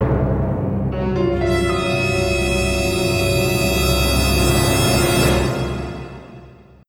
dramatic.wav